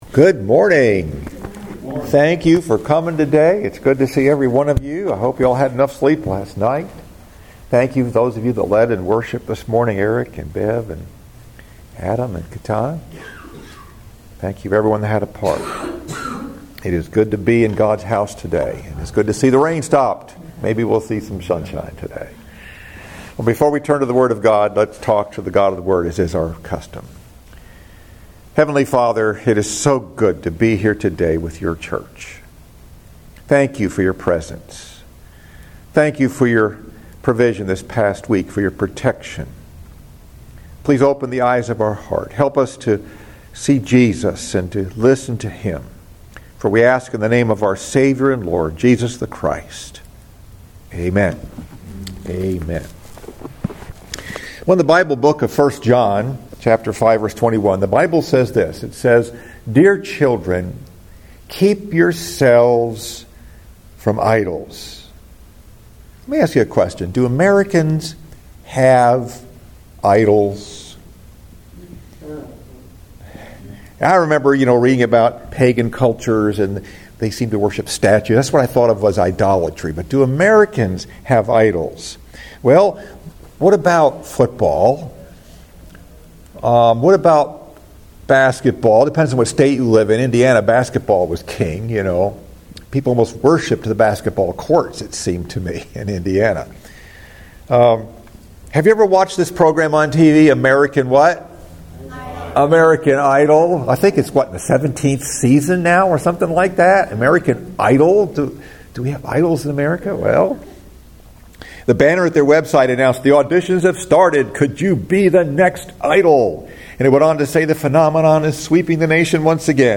Message: “American Idols” Scripture: 1 John 5:21 First Sunday of Lent